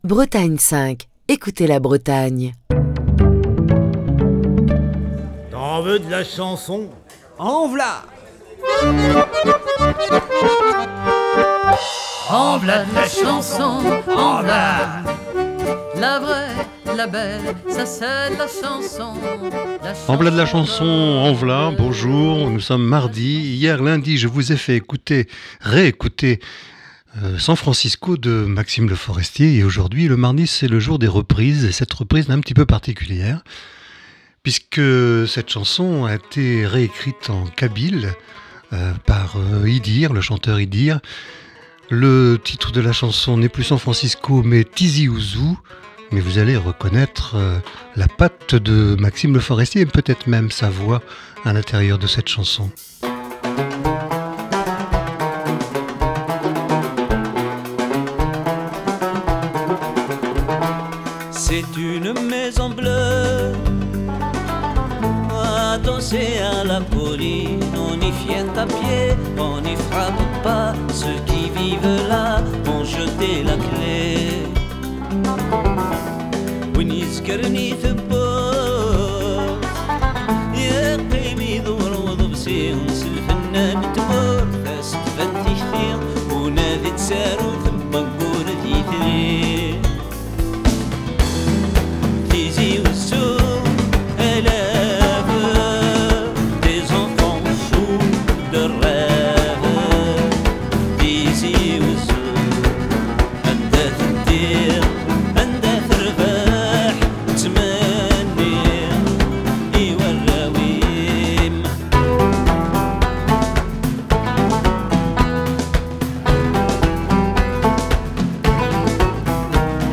reprise
chanteur kabyle